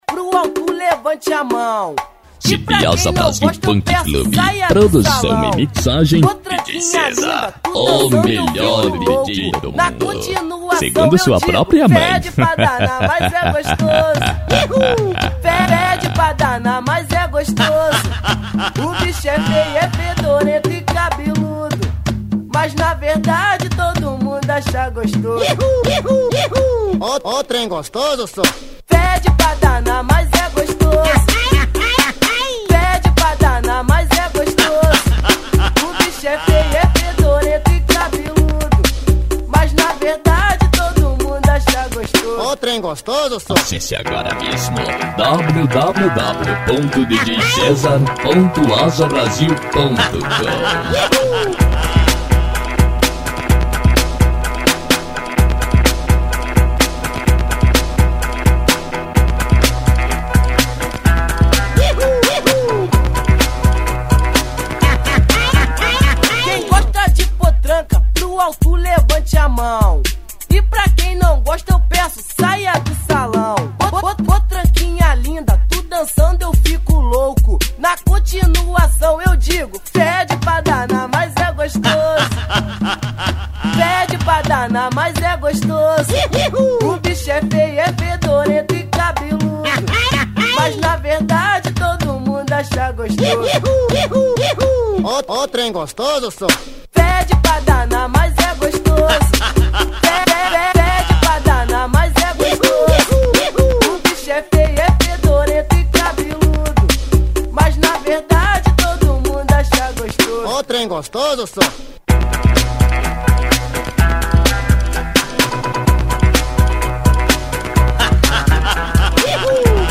Funk
Funk Nejo